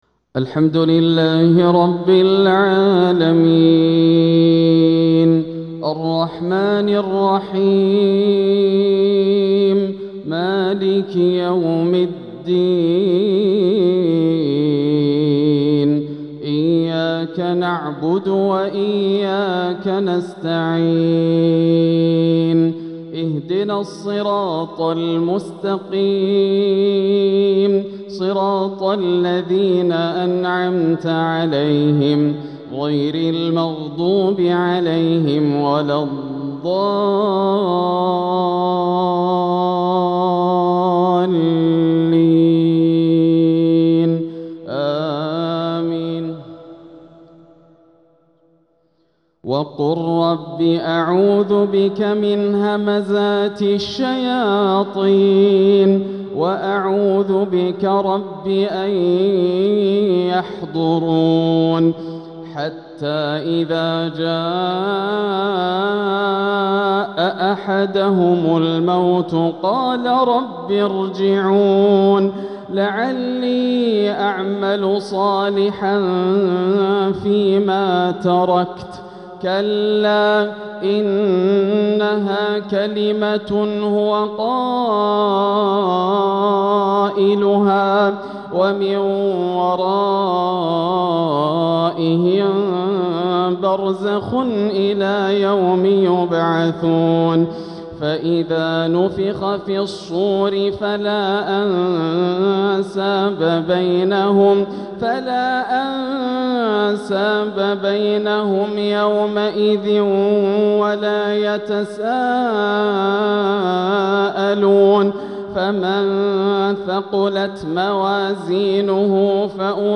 تلاوة لخواتيم سورة المؤمنون 97-118 | عشاء الجمعة 13 ربيع الأول 1447هـ > عام 1447 > الفروض - تلاوات ياسر الدوسري